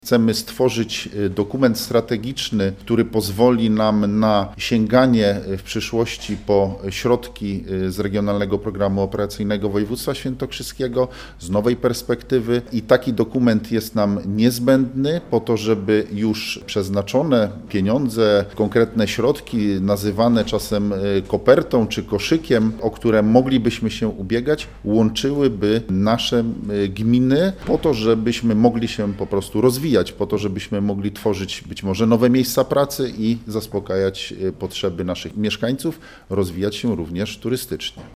Mówi burmistrz Sandomierza Marcin Marzec: